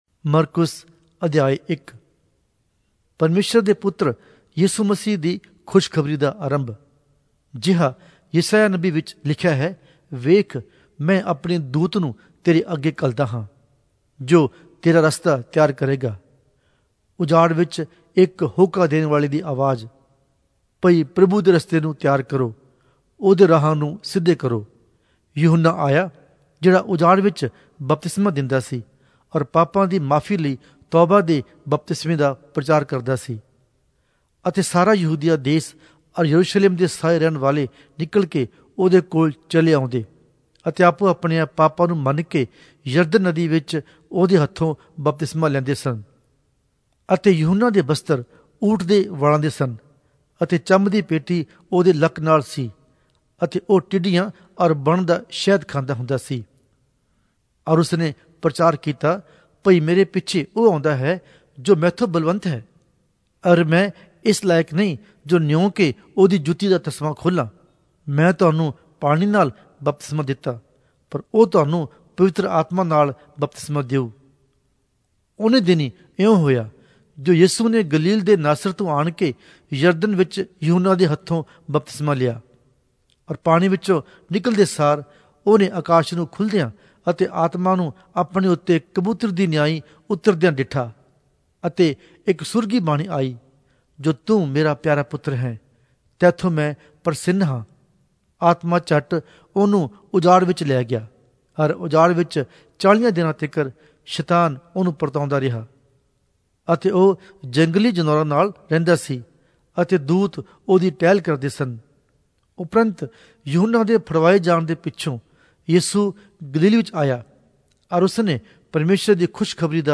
Punjabi Audio Bible - Mark 2 in Ocvbn bible version